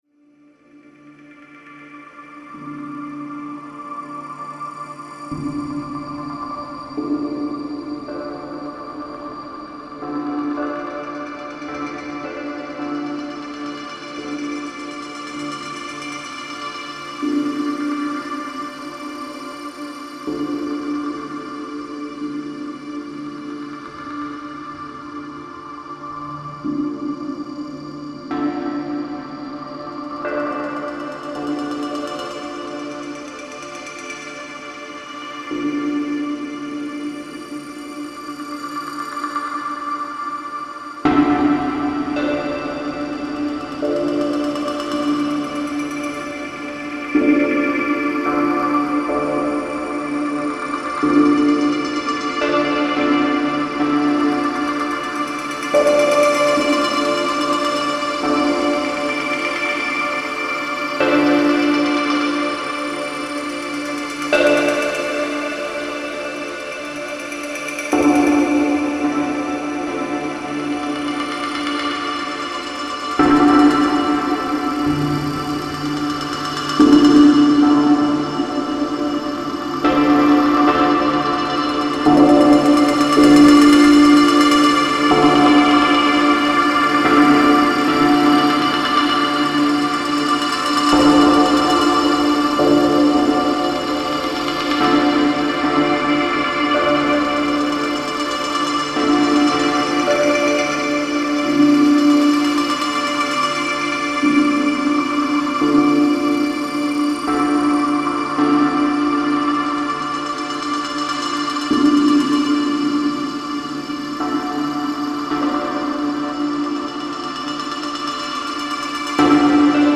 سبک مدیتیشن , موسیقی بی کلام